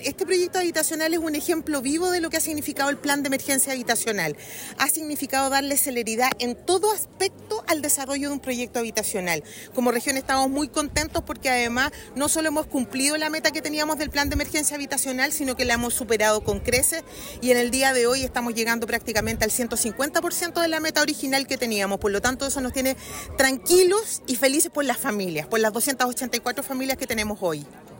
SERVIU_Parque-Bellavista-4_directora-Serviu.mp3